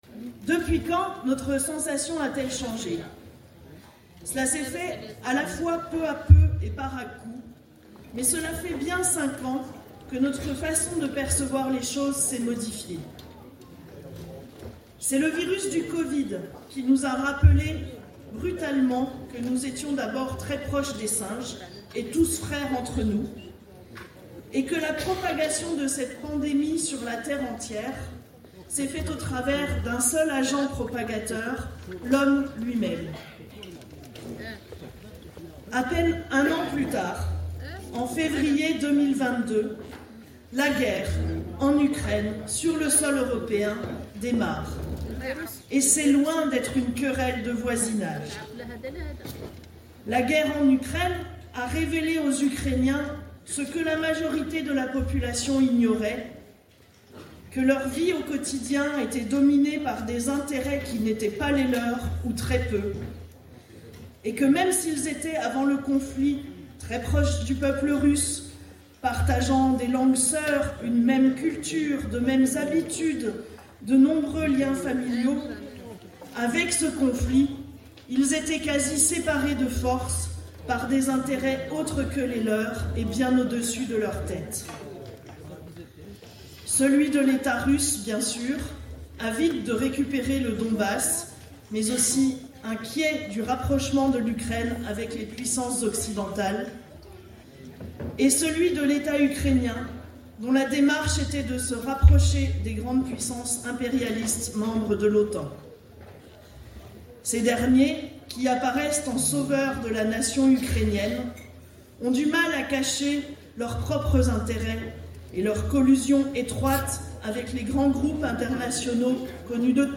Fête de Lutte ouvrière à Dijon